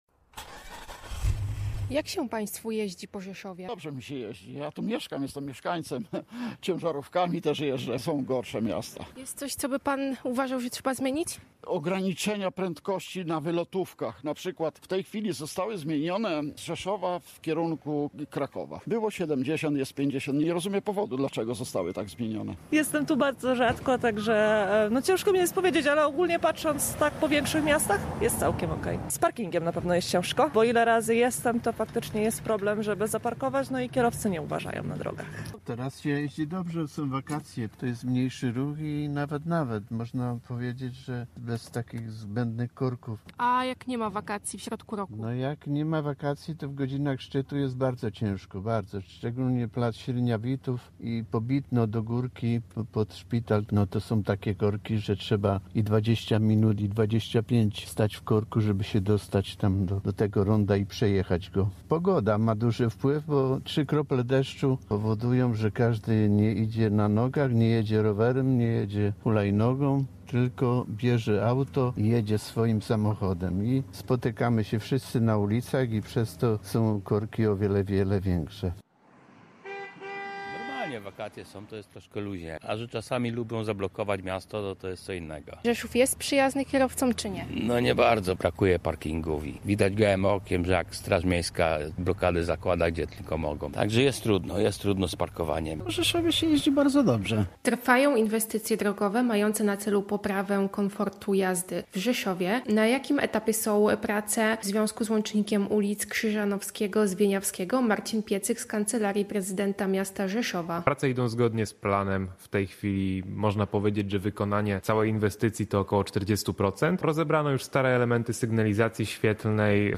Latem jeździ się lepiej, ale problemów nie brakuje — oceniają rzeszowscy kierowcy.